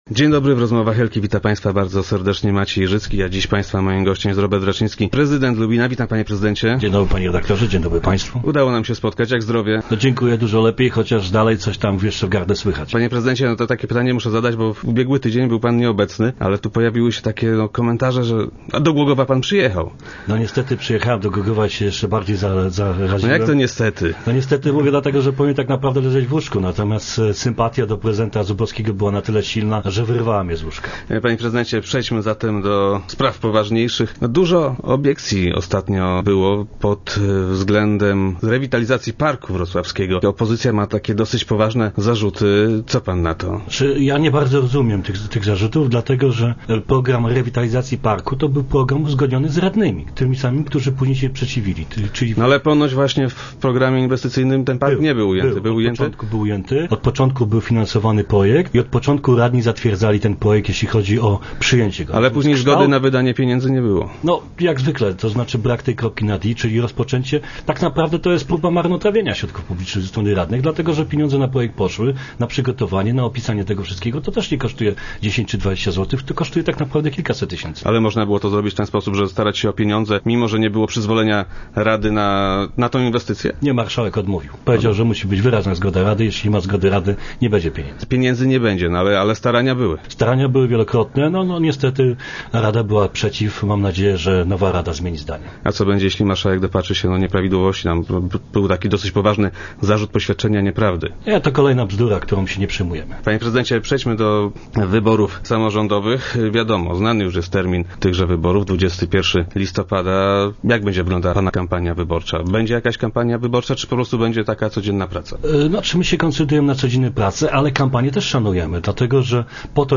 Robert Raczyński, prezydent Lubina i gość piątkowych Rozmów Elki ma nadzieję, że tak się właśnie stanie.
- Koncentrujemy się na codziennej pracy, ale kampanię wyborczą też szanujemy. Po to jest ten okres, żeby podsumować kadencję, ocenić ją oraz wyłonić kandydatów do sprawowania władzy przez kolejne lata - mówił na radiowej antenie prezydent Raczyński.